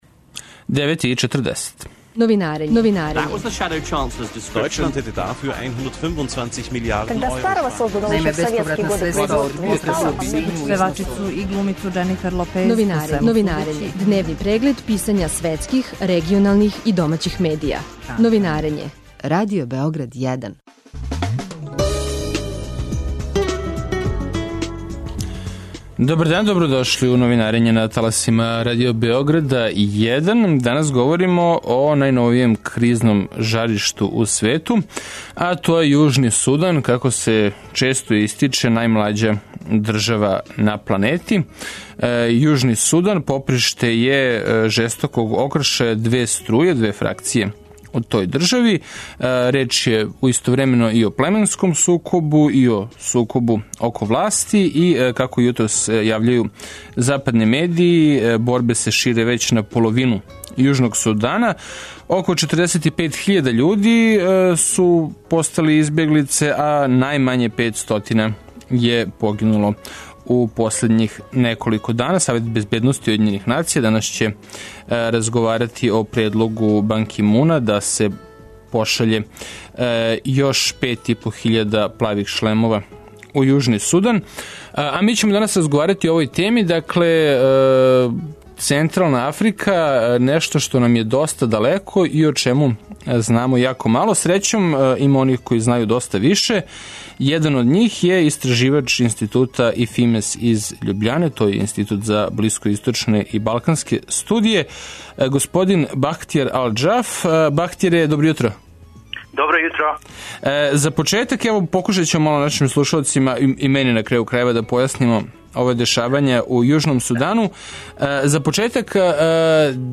Радио Београд 1, 09.40